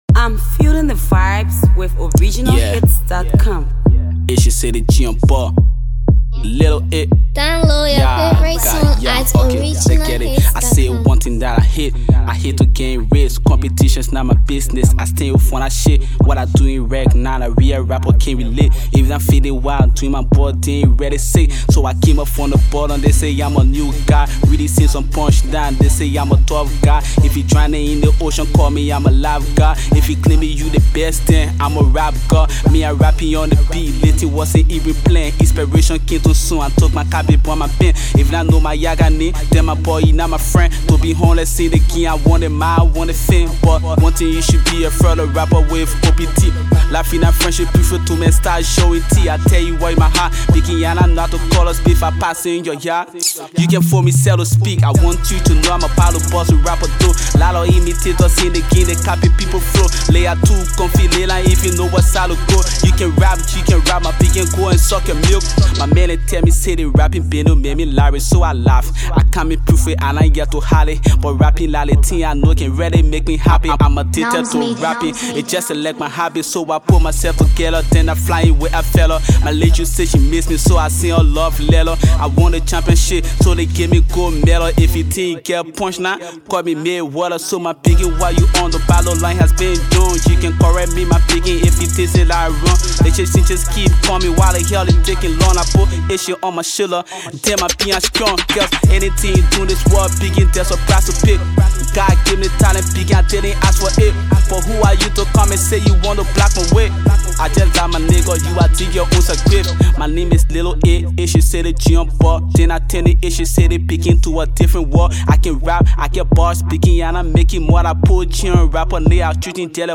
the gifted rapper
freestyle